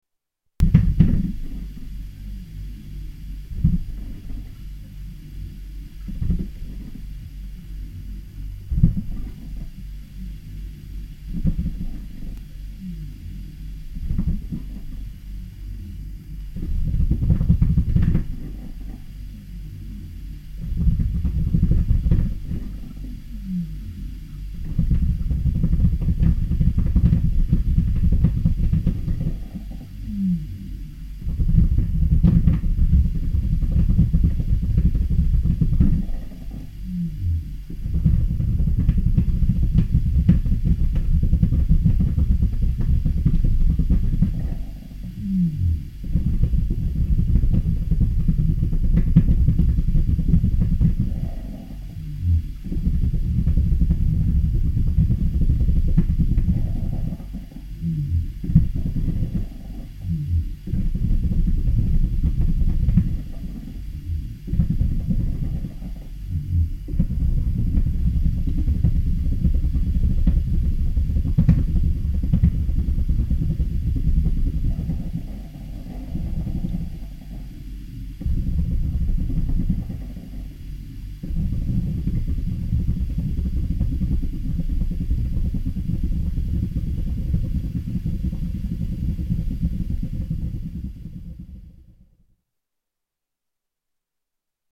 Inside-recording from a fridge